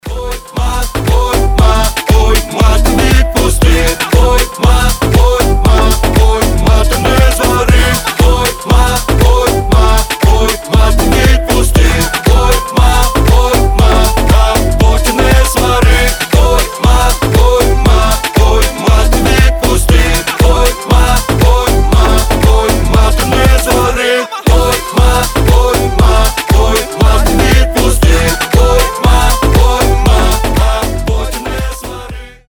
• Качество: 320, Stereo
ритмичные
заводные